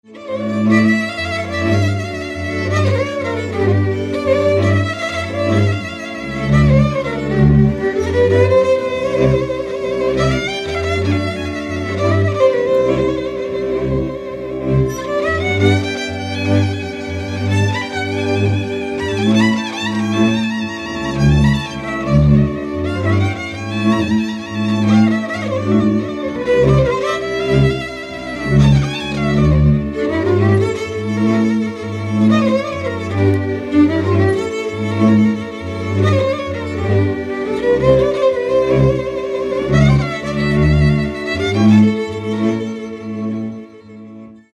Dallampélda: Hangszeres felvétel
Erdély - Szolnok-Doboka vm. - Ördöngösfüzes
hegedű
kontra
bőgő
Műfaj: Lassú cigánytánc
Stílus: 3. Pszalmodizáló stílusú dallamok